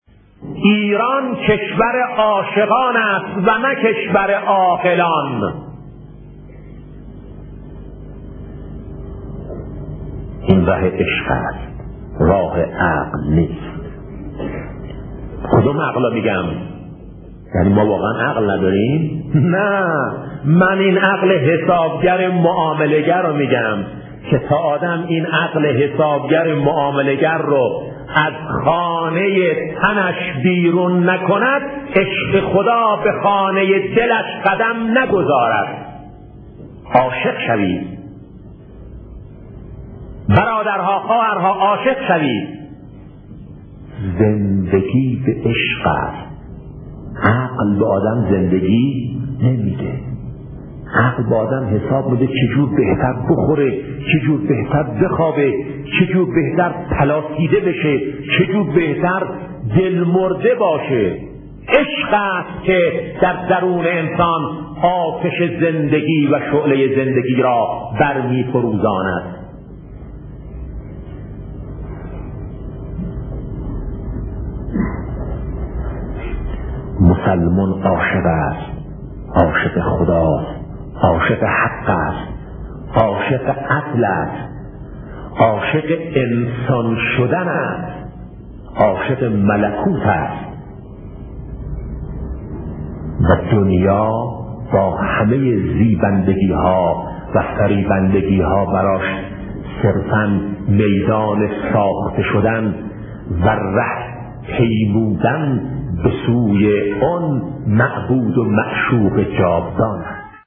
سخنرانی شهید بهشتی (ره) در خصوص حقیقت عشق